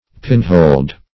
Pinhold \Pin"hold`\, n. A place where a pin is fixed.